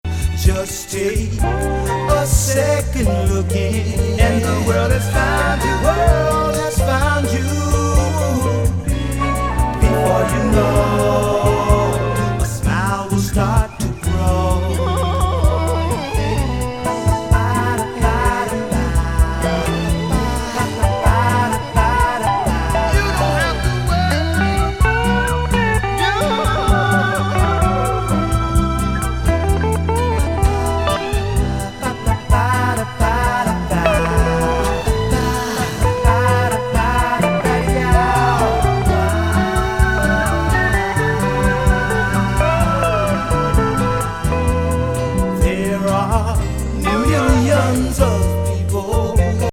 モダン・ディスコ
ボックス・メロウソウル